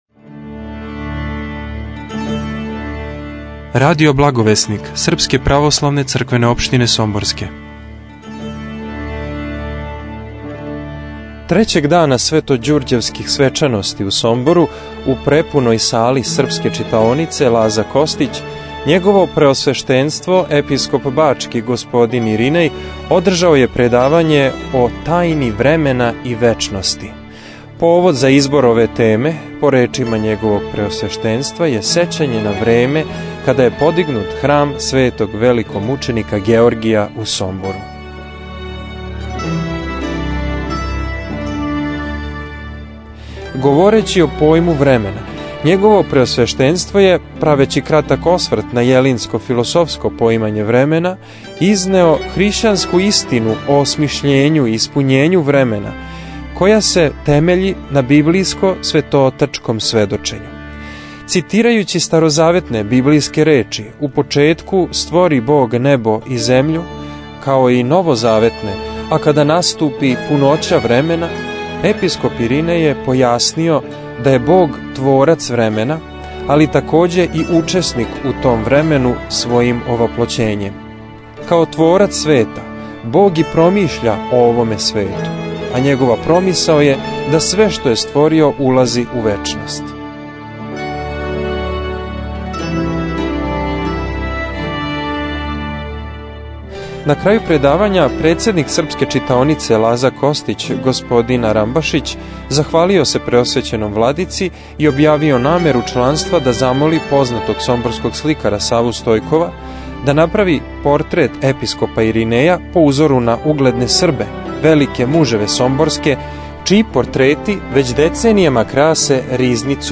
Трећега дана Светођурђевских свечаности у Сомбору, 4. маја 2011. године, пред мноштвом слушалаца у сали Српске читаонице Лаза Костић у Сомбору, Његово Преосвештенство Епископ бачки Господин др Иринеј одржао је предавање О тајни времена и вечности.